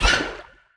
Sfx_creature_babypenguin_flinch_swim_02.ogg